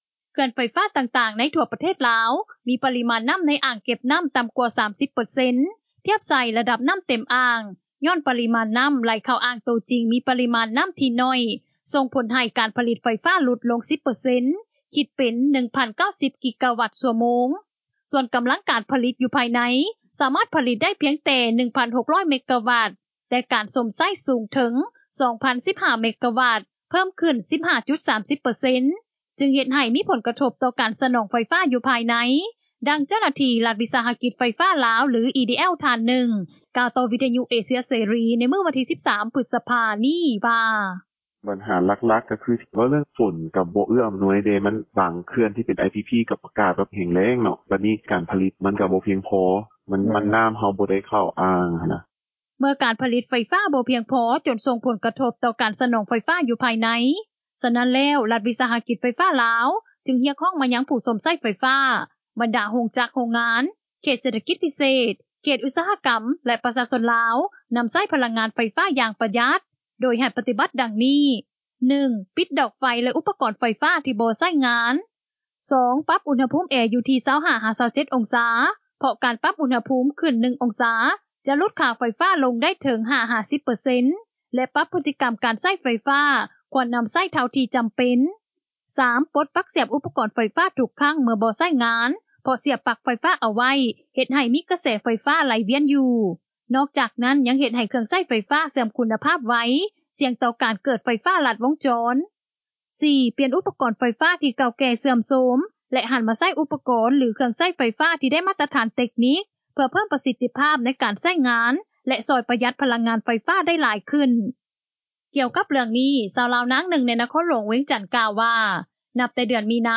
ດັ່ງເຈົ້າໜ້າທີ່ ລັດວິສາຫະກິດໄຟຟ້າລາວ ຫຼື EDL ທ່ານໜຶ່ງ ກ່າວຕໍ່ວິທຍຸເອເຊັຽເສຣີ ໃນມື້ວັນທີ 13 ພຶດສະພາ ນີ້ວ່າ:
ດັ່ງຊາວລາວນາງນີ້ ກ່າວຕໍ່ວິທຍຸເອເຊັຽເສຣີ ໃນມື້ດຽວກັນນີ້ວ່າ: